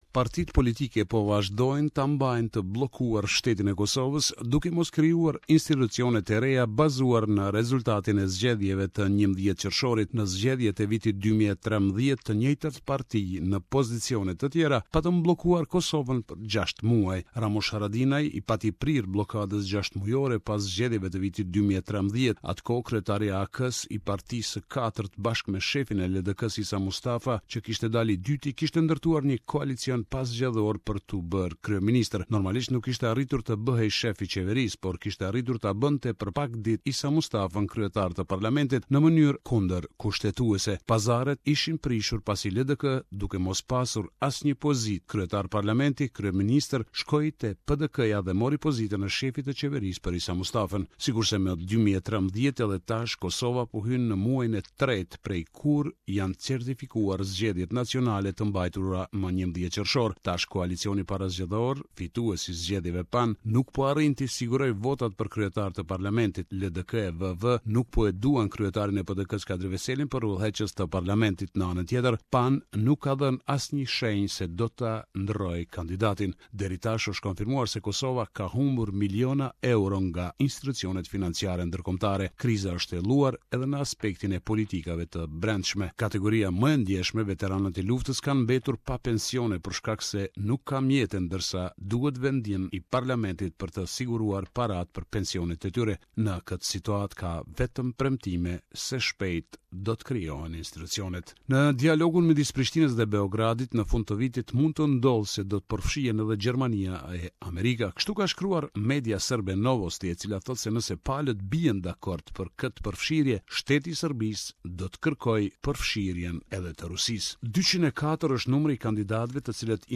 Report from Prishtina.